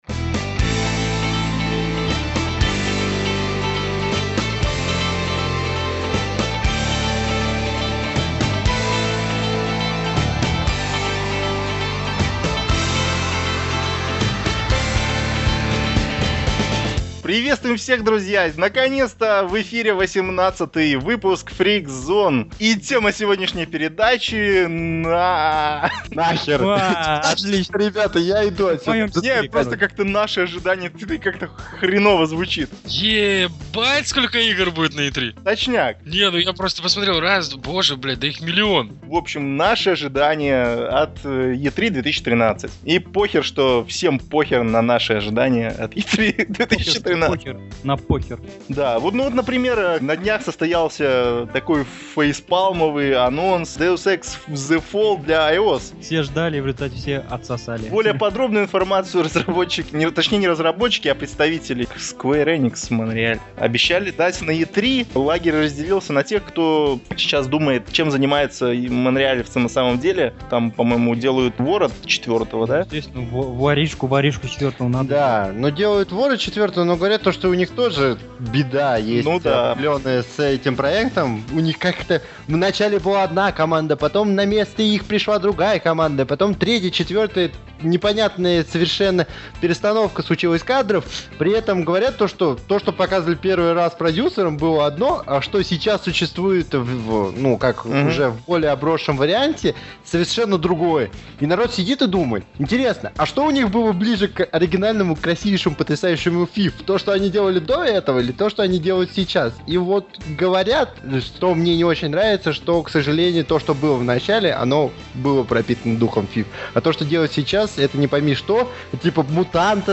Пожалуй, самый безбашенный проект на нашем сайте, потому что только здесь администрация и авторы сайта в свободной разговорной форме, почти без цензуры, обсуждают дела былые и насущные в мире видеоразвлечений.